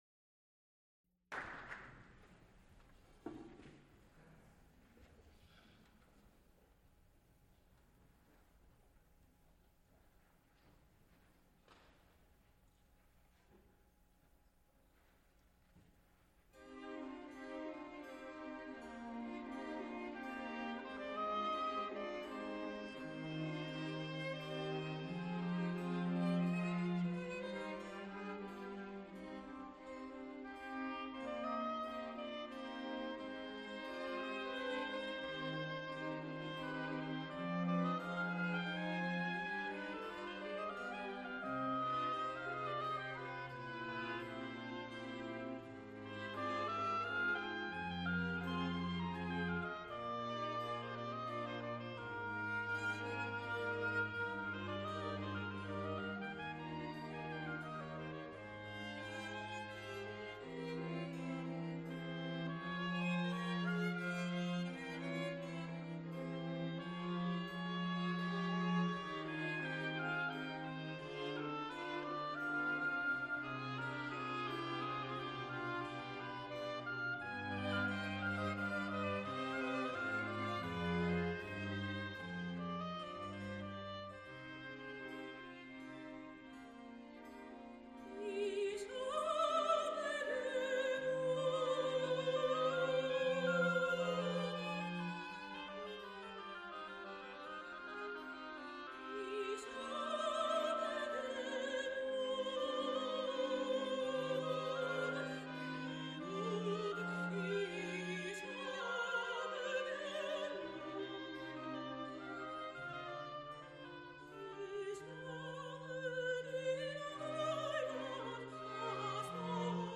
Chamber music for voice and instruments | Digital Pitt
mezzo soprano
flute
piano
Recorded live May 26, 1977, Frick Fine Arts Auditorium, University of Pittsburgh.
Extent 2 audiotape reels : analog, half track, 7 1/2 ips ; 7 & 12 in.
Songs (Medium voice) with instrumental ensemble